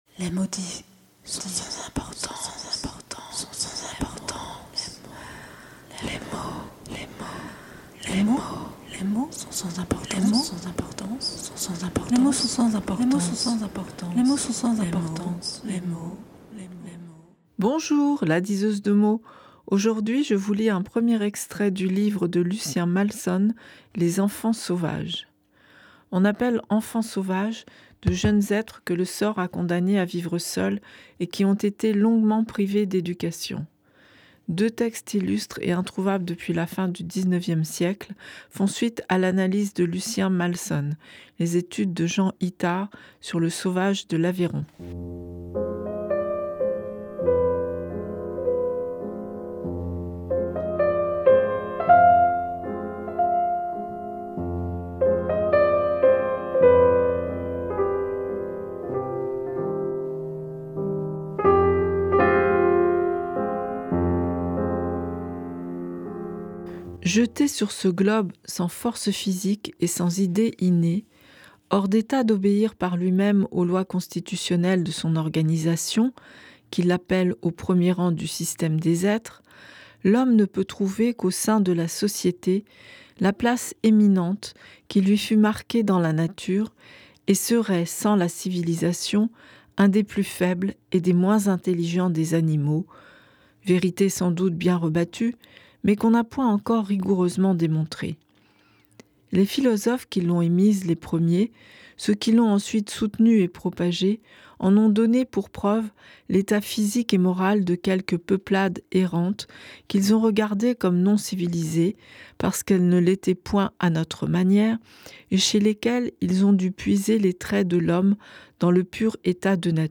Musique : Erik Satie - Gnossienne n°1 Chanson : Comptine enfantine Documents joints Les mots dits - 5 mars 2018 (MP3 - 30.3 Mo) SALON D'ECOUTE Aucun audio !